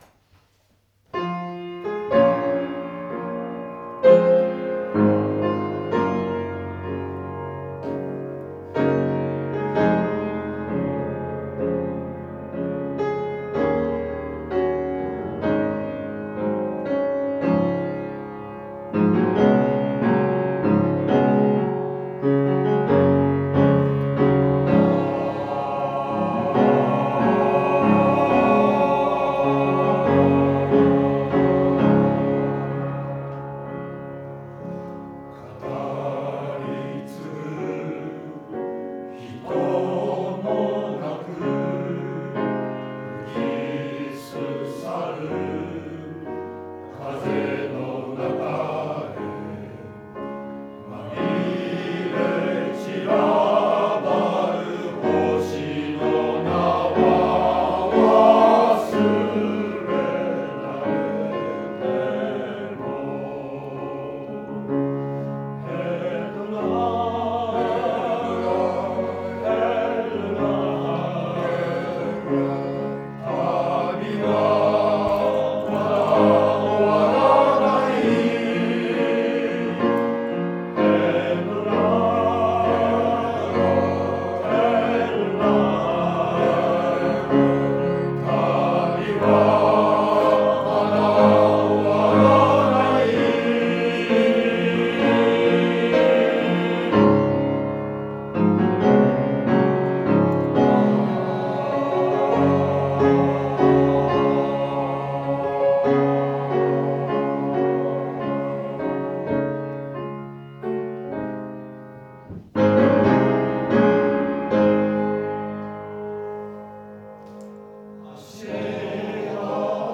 合唱祭前の最後の練習、湖北台東小学校
「ヘッドライト・テールライト」 は1)の演奏のほうが良かったです。